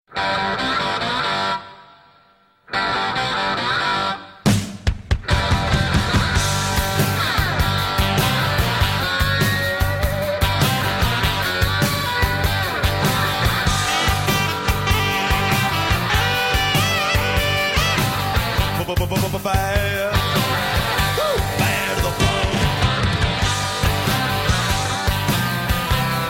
آهنگ گانگستری گوشی خفن پسرانه لاتی خارجی بی کلام